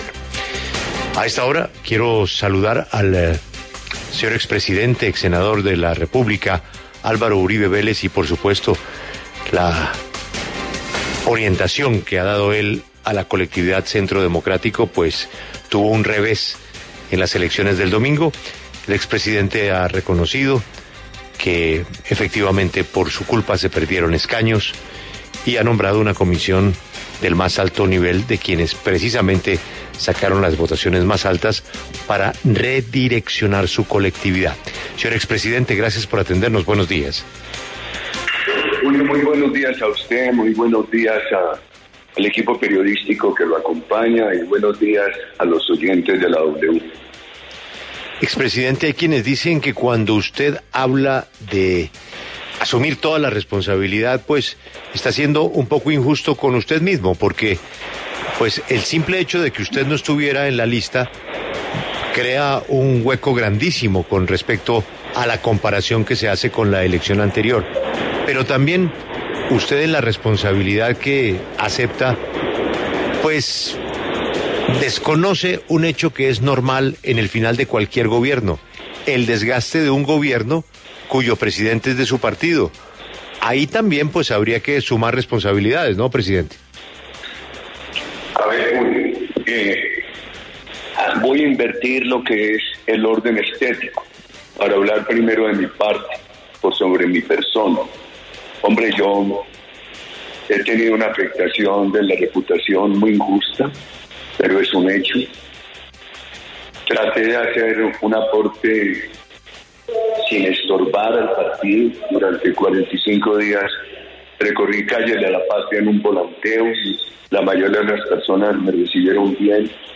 En diálogo con La W, el expresidente Álvaro Uribe, jefe natural del Centro Democrático, aseguró que hay que escuchar a otros candidatos, como Ingrid Betancourt.